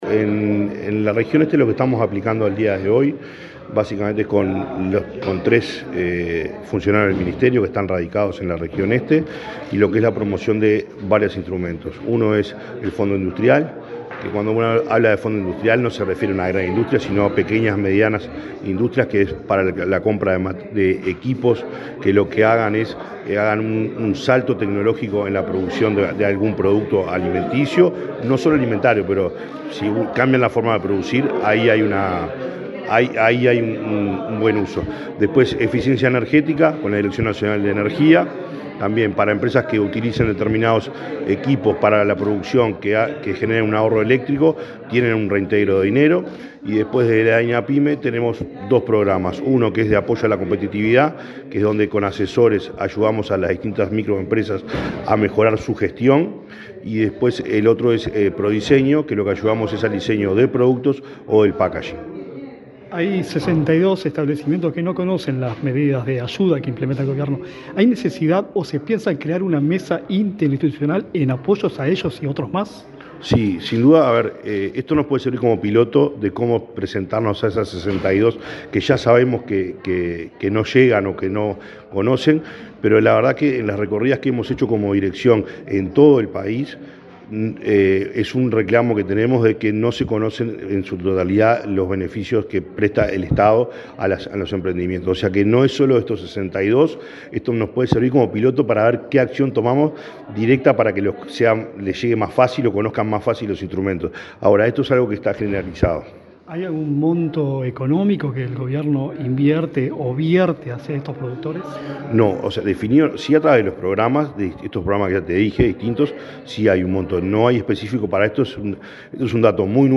Entrevista al director de Artesanías, Pequeñas y Medianas Empresas del MIEM, Gonzalo Maciel
El director nacional de Artesanías, Pequeñas y Medianas Empresas del Ministerio de Industria, Energía y Minería (MIEM), Gonzalo Maciel, dialogó con Comunicación Presidencial, este lunes 8 en Minas, departamento de Lavalleja, luego de la presentación de los resultados de la pesquisa de emprendimientos industriales y microindustrias rurales en la región este, vinculadas al sector alimentos.